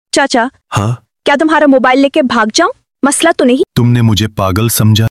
Monkey Vlogger & Grandpa Talk sound effects free download
In a hilarious twist of roles, a cheeky monkey takes over the vlog cam while an elderly man watches in amused disbelief! Caught mid-conversation, the two seem to be deep in discussion — perhaps about bananas or the latest TikTok trends?